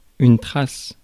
Ääntäminen
France: IPA: [tʁas]